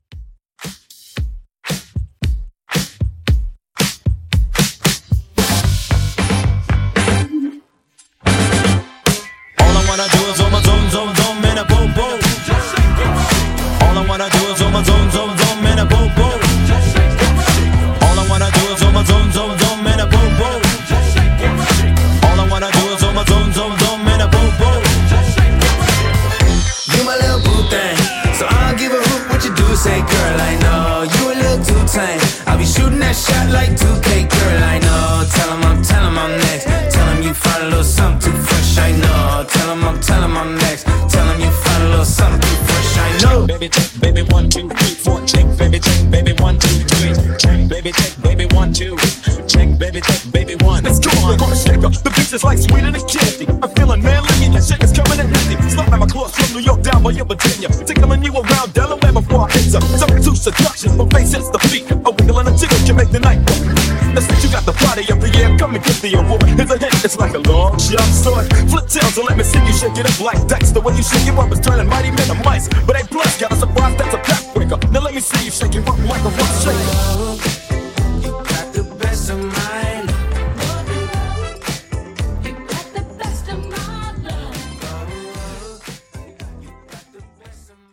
BPM: 114 Time